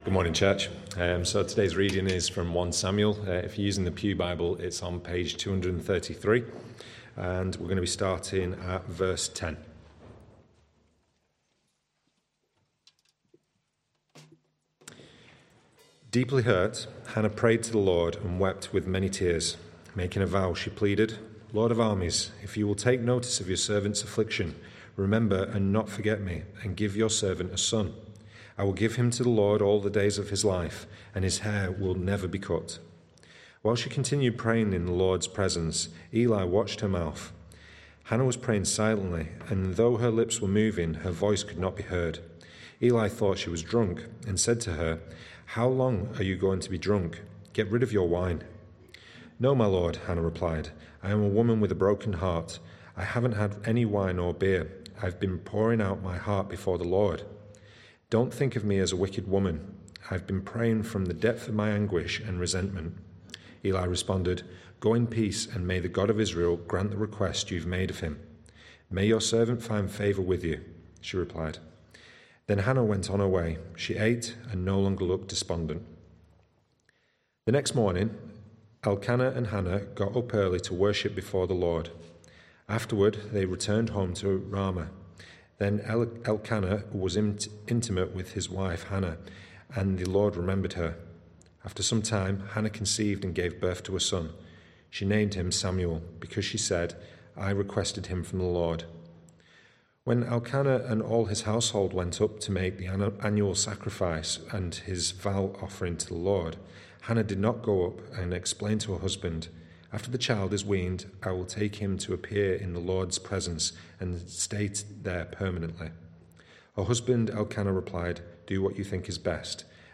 Home - TCM Baptist Church